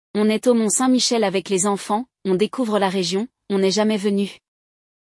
No episódio desta semana, nós acompanhamos dois amigos que estão falando ao telefone, e um deles está na Normandie.